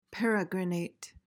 PRONUNCIATION:
(PER-uh-gruh-nayt)